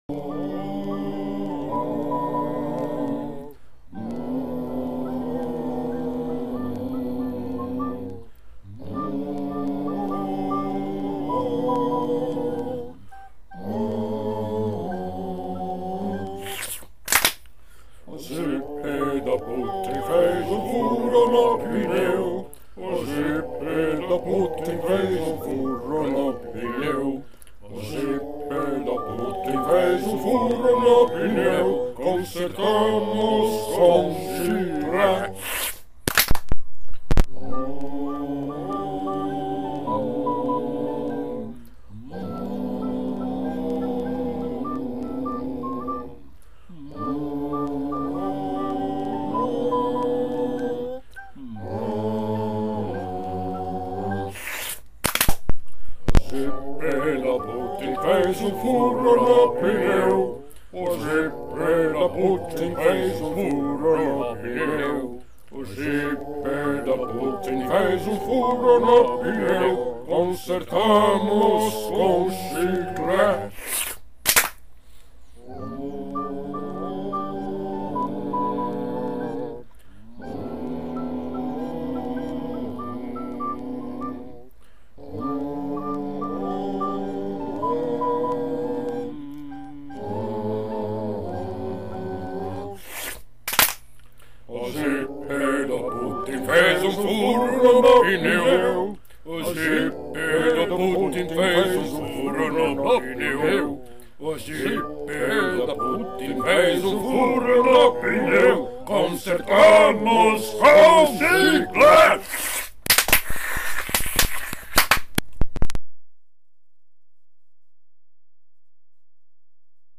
É só acompanhar o nosso “Coro do Exército Vermelho” fajuto, gravado em app chinês e com doses crescentes de vodka, que convocamos para participar de mais este vídeo do canal Forças de Defesa no Youtube (já se inscreveu?